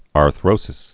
(är-thrōsĭs)